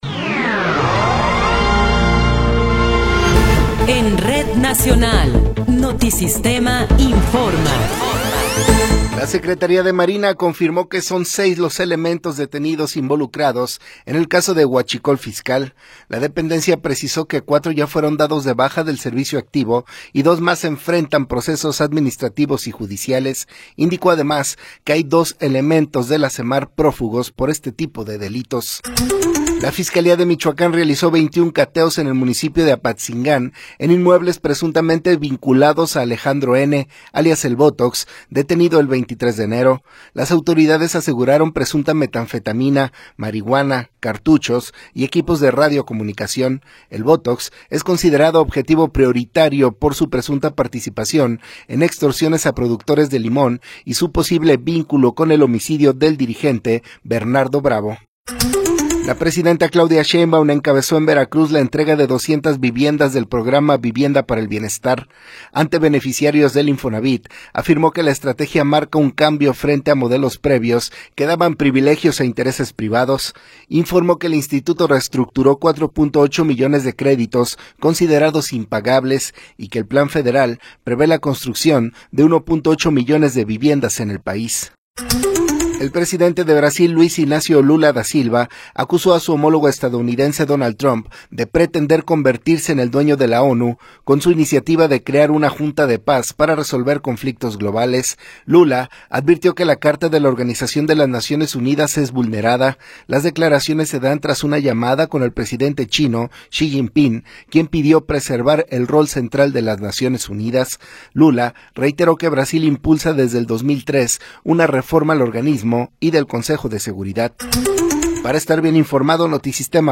Noticiero 10 hrs. – 24 de Enero de 2026
Resumen informativo Notisistema, la mejor y más completa información cada hora en la hora.